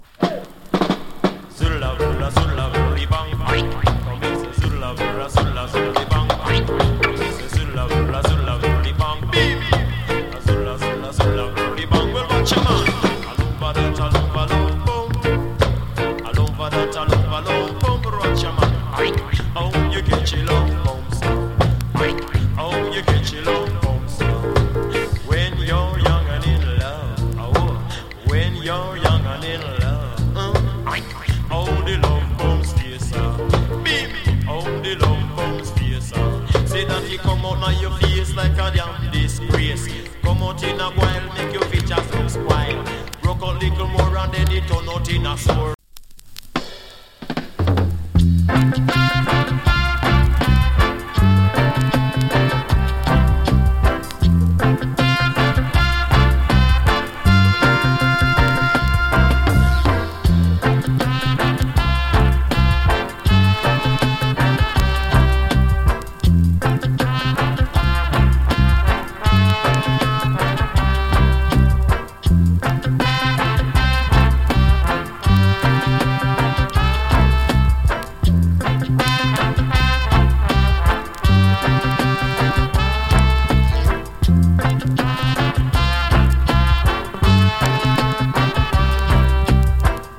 チリノイズわずかに有り。
RIDDIM
FINE INST